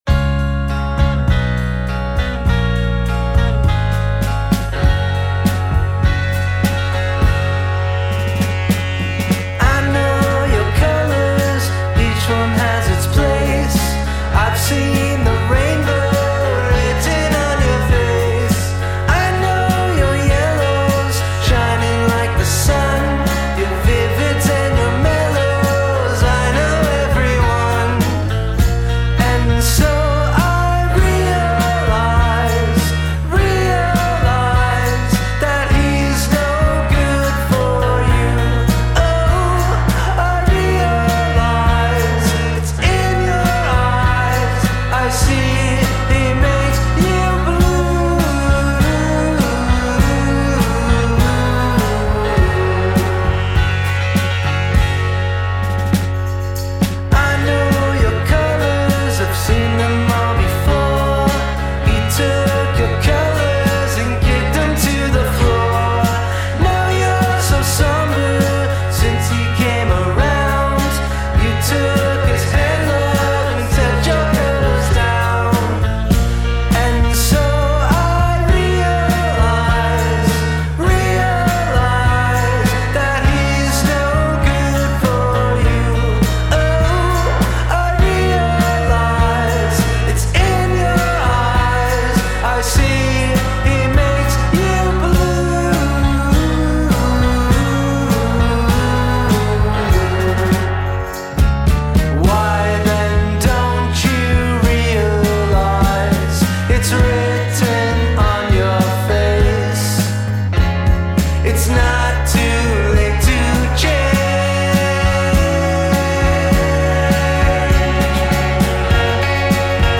Power pop homage